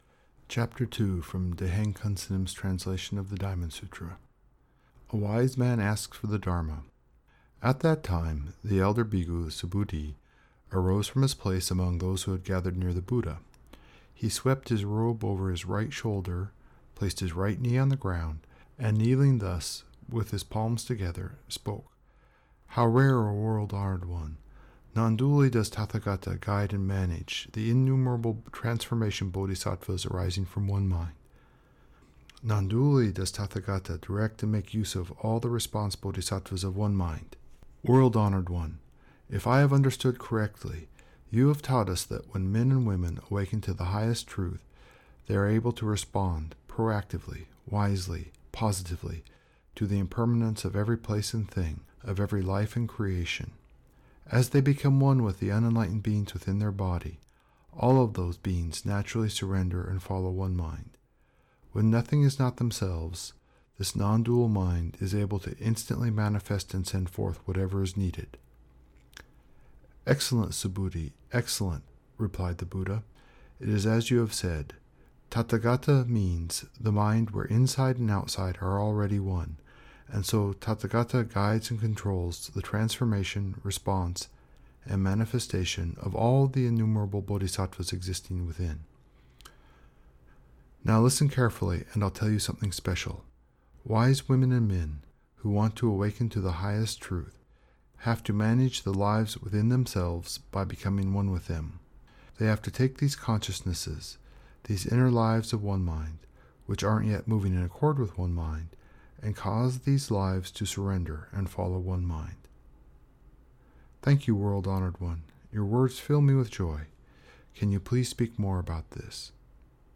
Here’s the readings and Dharma talk for the second week of this series of talks about what our karma is and how we can overcome it.